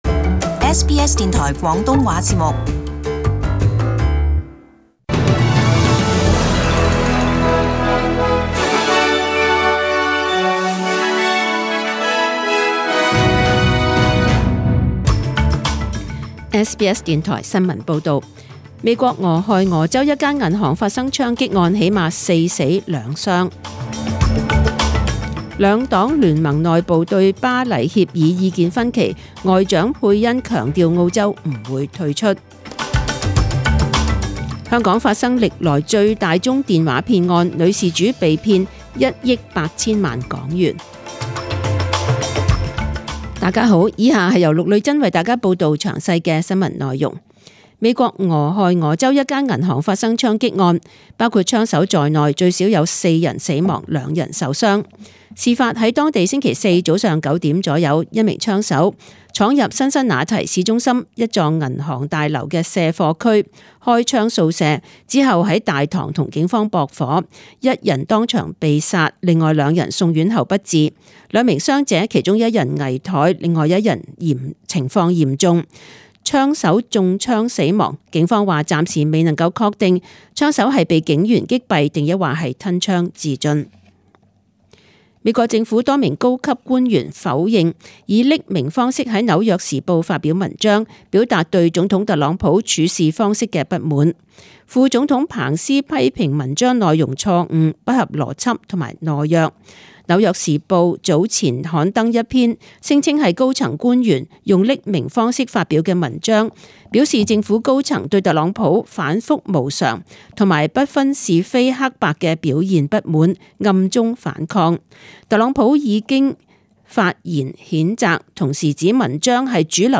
SBS中文新闻 （九月七日）